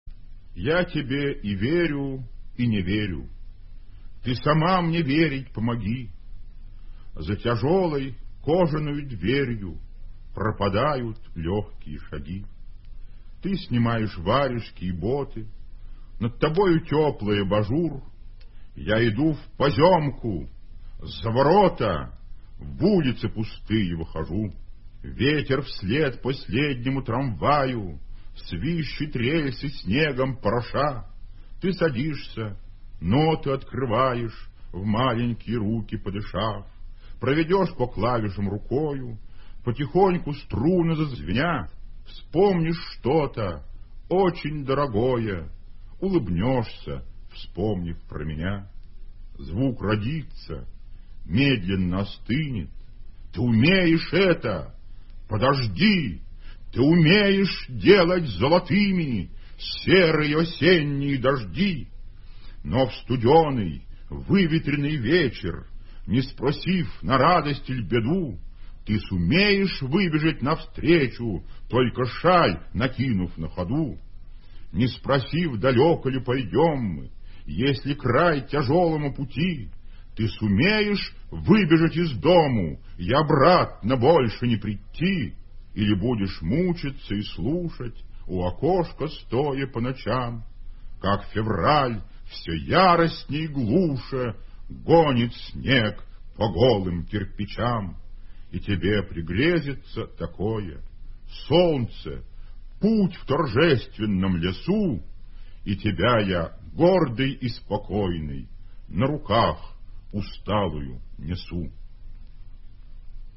Solouhin-Ya-tebe-i-veryu-i-ne-veryu.-chitaet-avtor-stih-club-ru.mp3